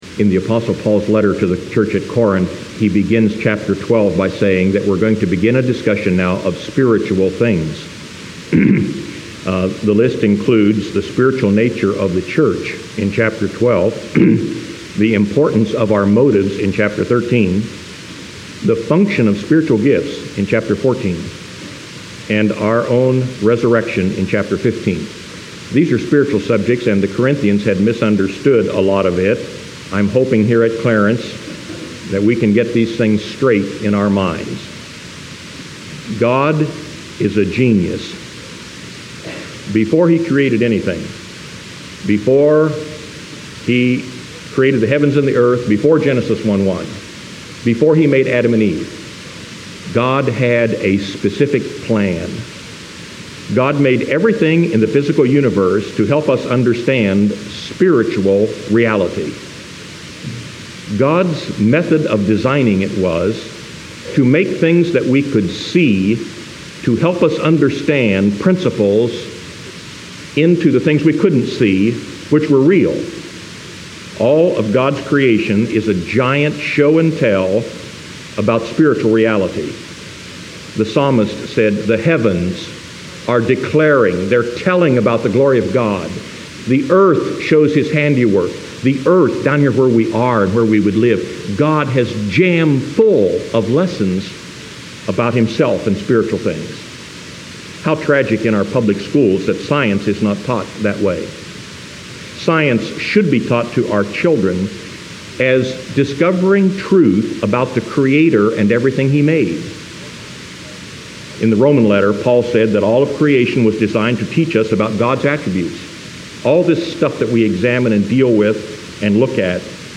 1 Corinthians 12:12-27 Preacher